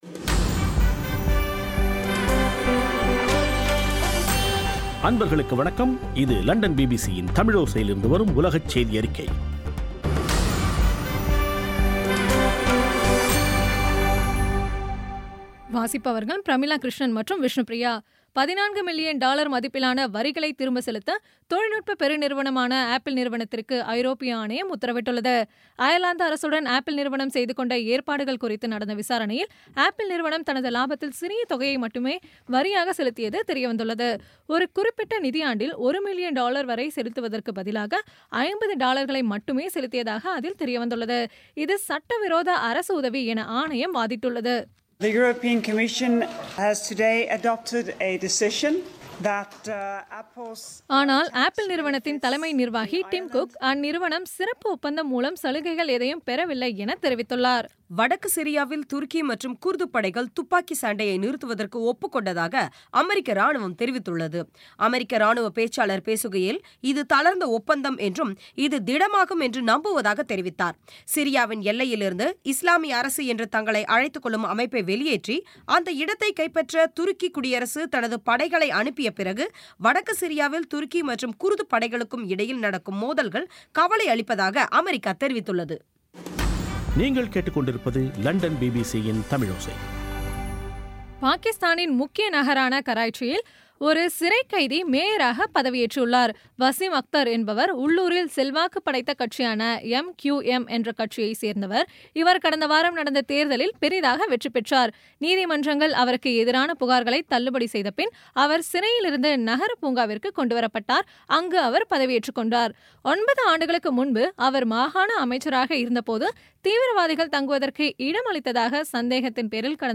பி பி சி தமிழோசை செய்தியறிக்கை (30/08/2016)